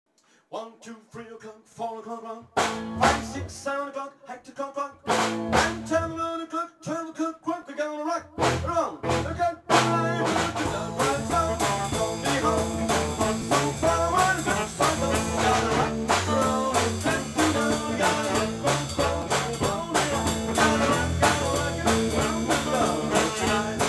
Enregistrement mini-disc (29.12.2001)